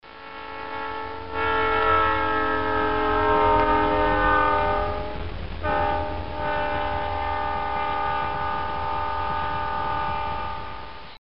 trainpassslow.mp3